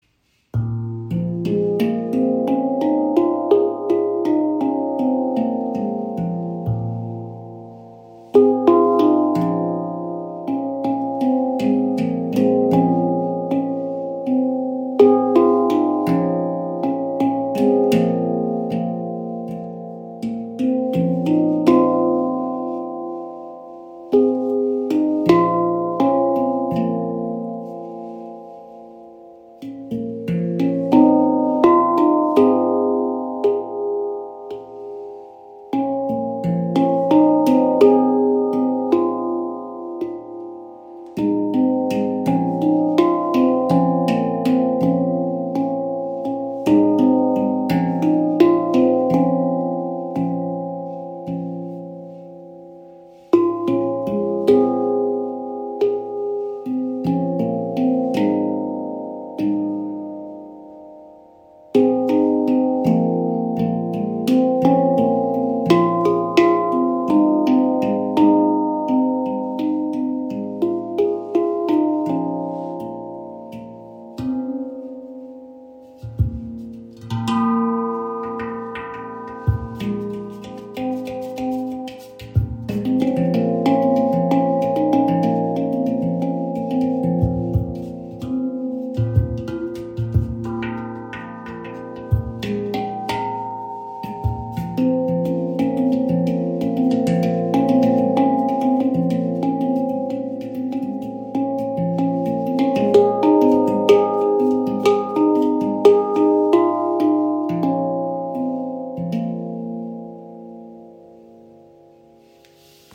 Handpan ShaktiPan | Bb2 Celtic | 432 Hz im Raven-Spirit WebShop • Raven Spirit
Klangbeispiel
Diese ShaktiPan aus Ember Steel in Bb Celtic 432 Hz har eine sehr beliebte harmonische Stimmung, wobei alle Klangfelder sehr gut gestimmt sind.
Edelstahl (Stainless Steel) gehört zu den oft gewählten Materialien bei Handpans, da es eine schöne Klangfarbe und eine angenehme, besonders lange Schwingung (den sogenannten Sustain) erzeugt.